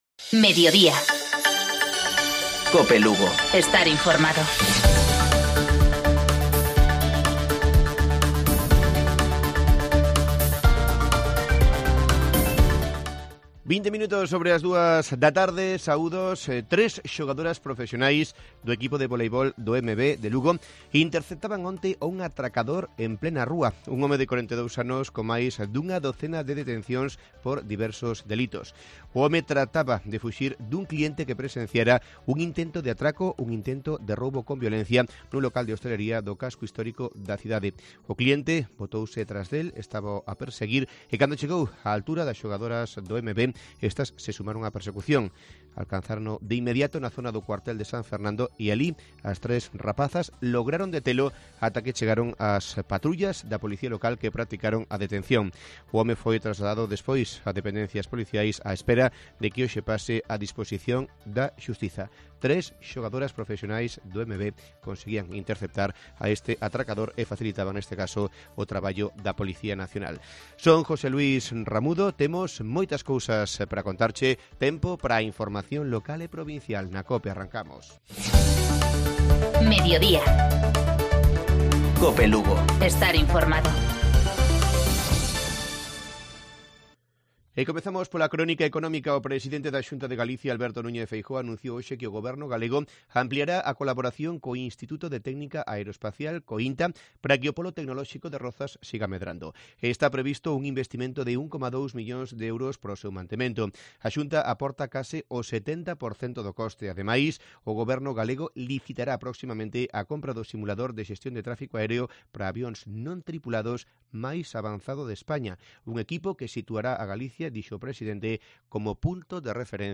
Segunda edición. Informativo Mediodía Cope Lugo.